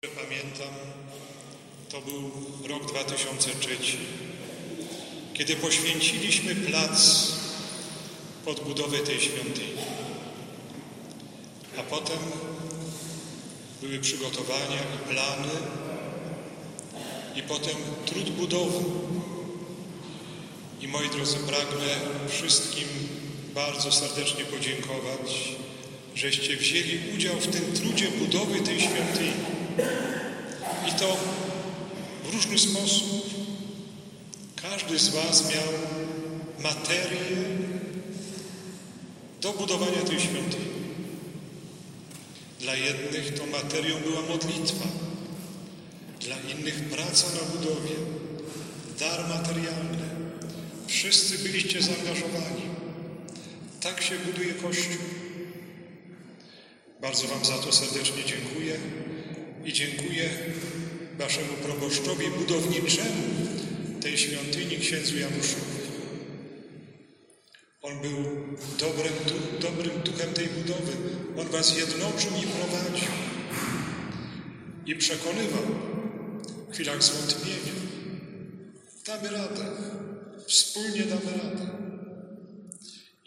Uroczystość poświęcenia nowego kościoła w Starym Sączu.